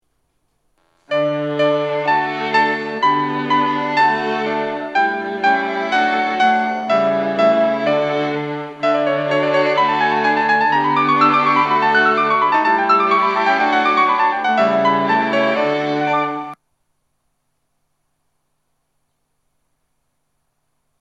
発車メロディー
余韻切りです。先行の特急に左右されます。
先行が定時で通過すれば余韻までは鳴りやすいです。
たまに駅員放送が被ることがあります。